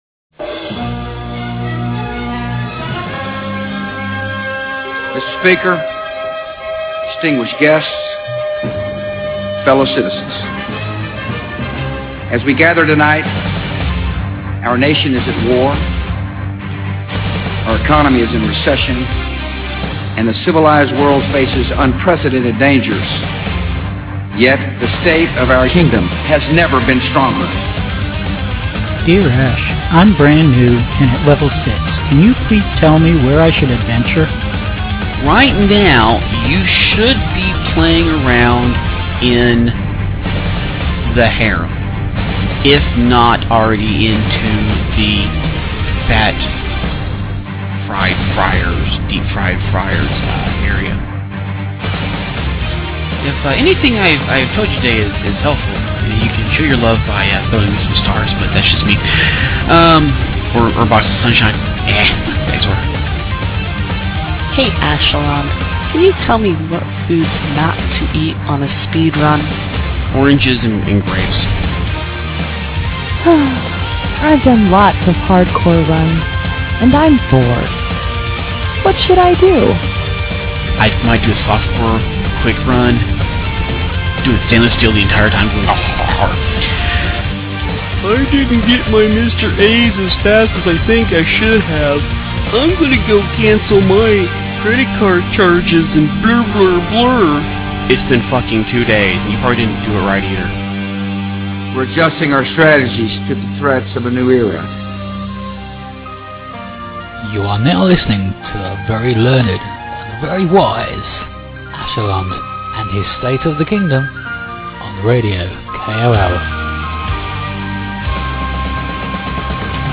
Homeopathic Powder changes. Note : This show is missing segments due to network errors.